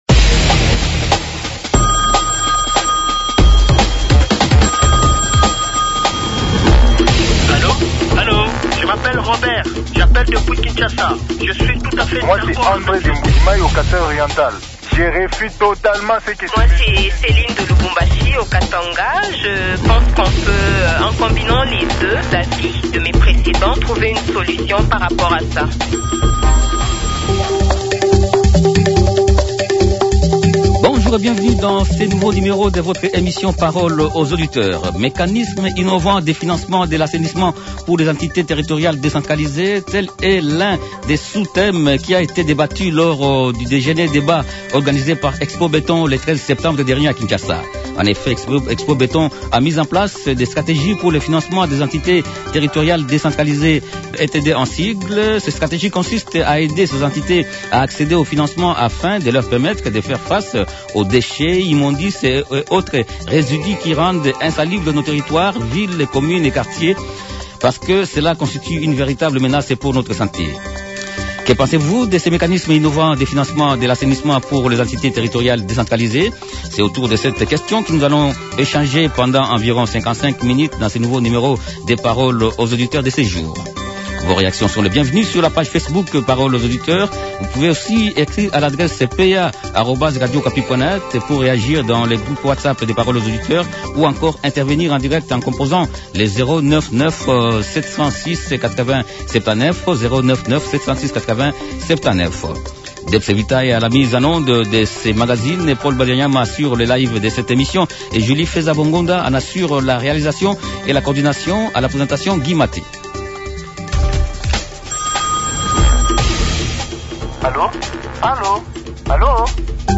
Les auditeurs ont échangé avec